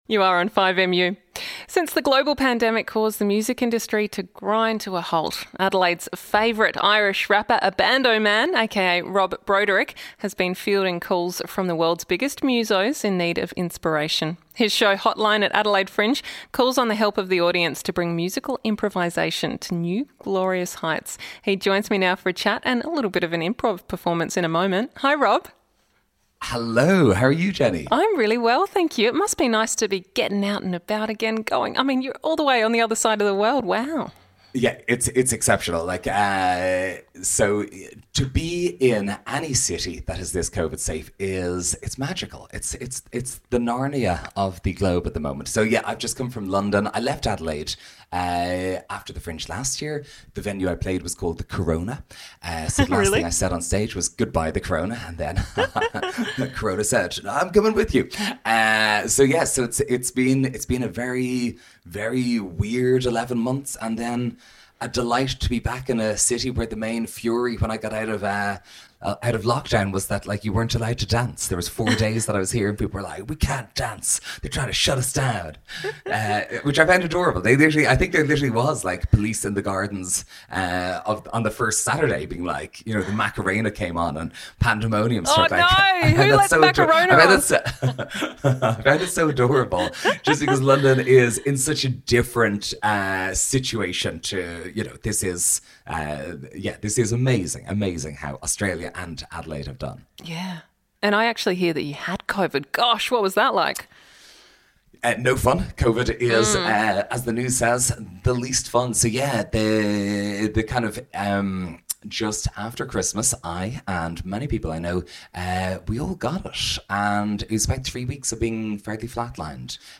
Improv Comedy with Irish Rapper